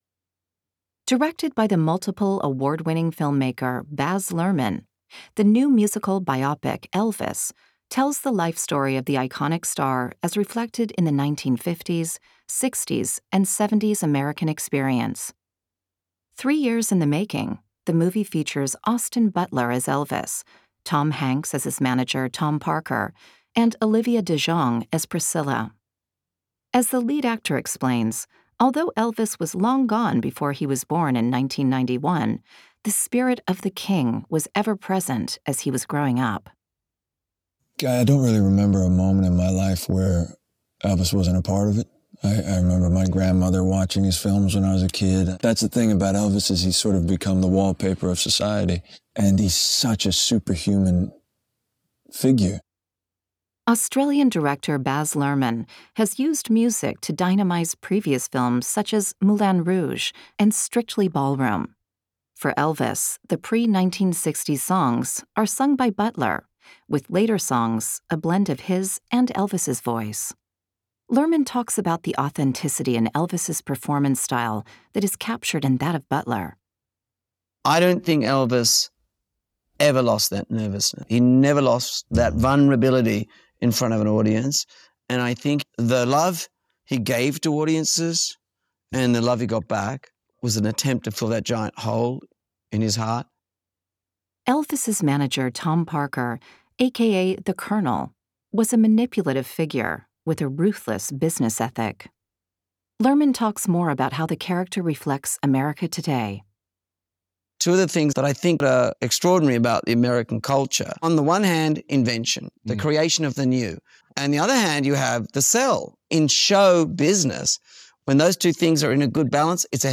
Austin Butler (American accent):
Baz Luhrmann (Australian accent): I don’t think Elvis ever lost that nervousness, he never lost that vulnerability in front of an audience.